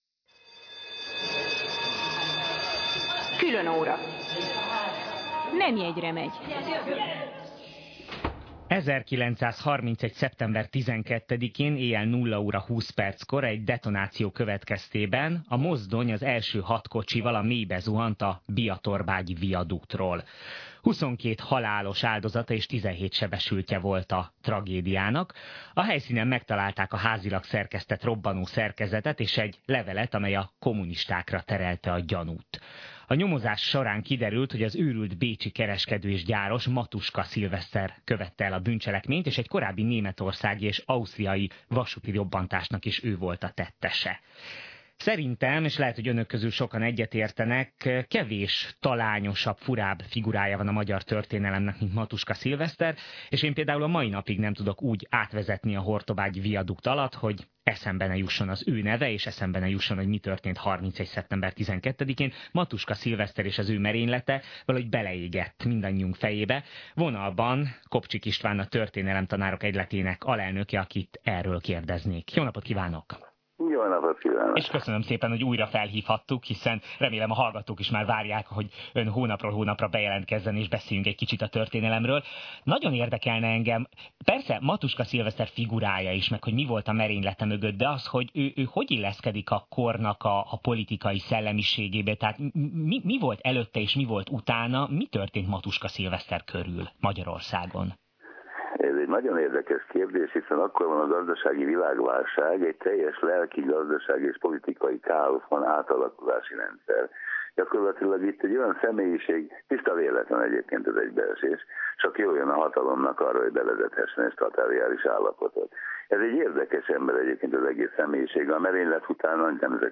Az interjú itt meghallgatható és letölthető